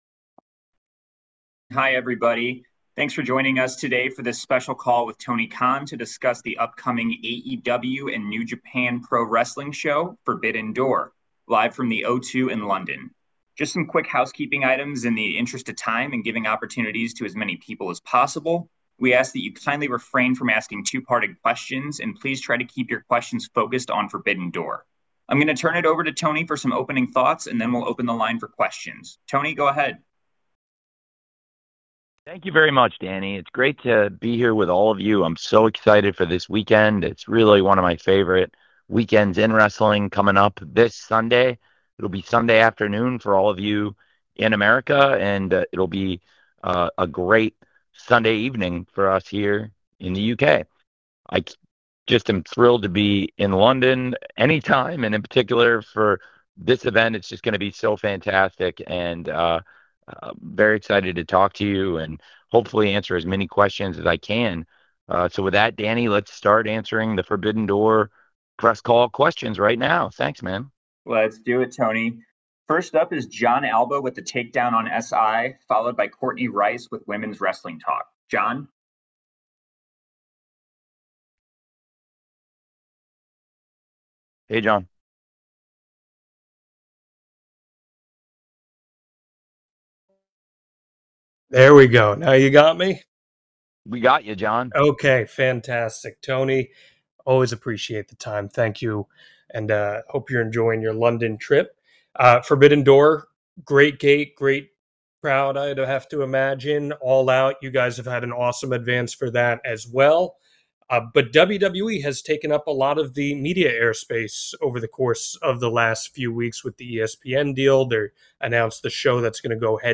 Media call with AEW's Tony Khan to discuss Forbidden Door 2025, Bozilla & working with CMLL, New Japan and Stardom.
Tony Khan, President, General Manager and Head of Creative for All Elite Wrestling, spoke to the media on Thursday August 21, 2025 to discuss AEW x NJPW x CMLL x Stardom Forbidden Door 2025.